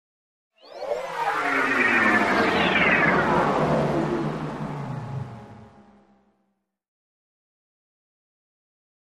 Sweep Electronic Tweet Descending Sweep with Liquid Ripple, Static Buzz